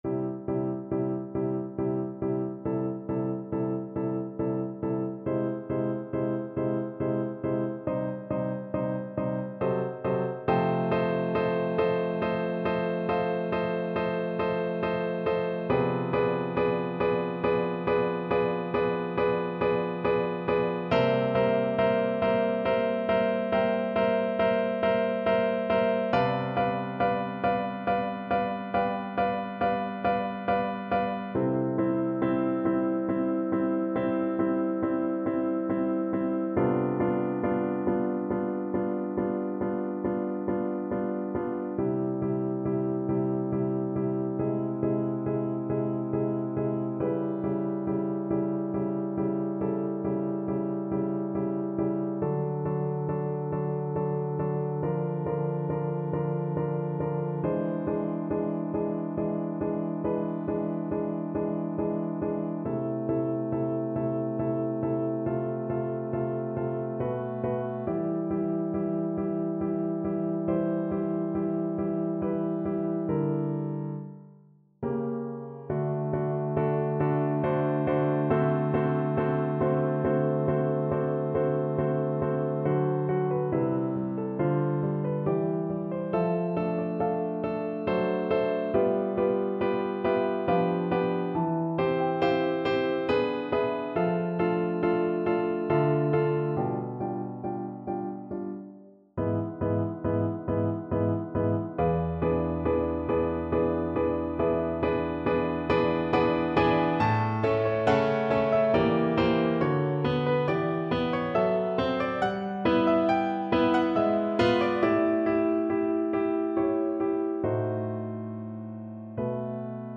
Play (or use space bar on your keyboard) Pause Music Playalong - Piano Accompaniment Playalong Band Accompaniment not yet available transpose reset tempo print settings full screen
Oboe
3/4 (View more 3/4 Music)
C major (Sounding Pitch) (View more C major Music for Oboe )
Sehr mŠ§ig bewegt, aber nie schleppend = c.69
Classical (View more Classical Oboe Music)